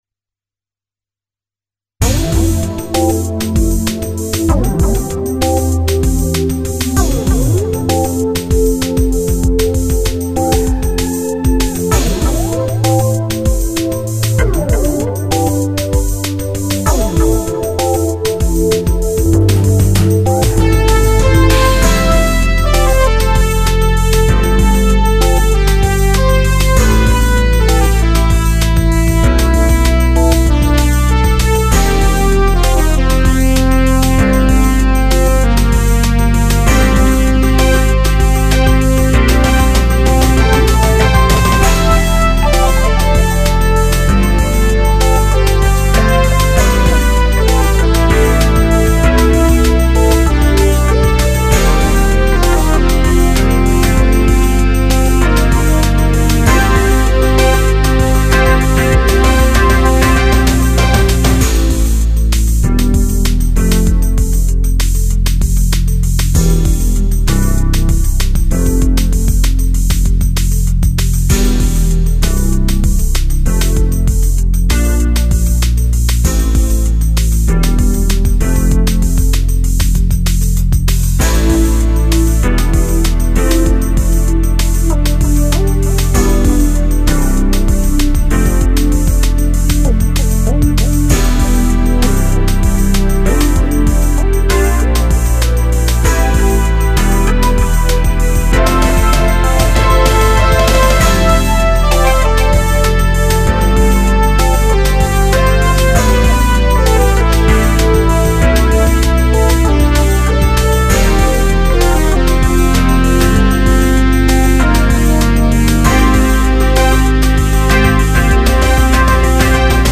SC88をMOTU828に接続し、Cubase SX3で録音。
すべてSC88の出音だが、SXでマルチバンドコンプ（プリセット「FM Station」）のみ適用。